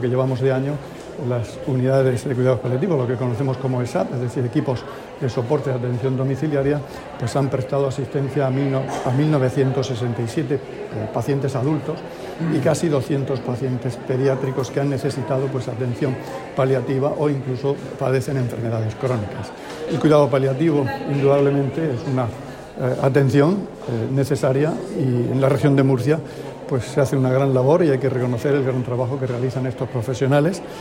Declaraciones del consejero de Salud, Juan José Pedreño, sobre los cuidados paliativos en la Región de Murcia. [MP3]
Inauguración de las III Jornadas regionales de cuidados paliativos.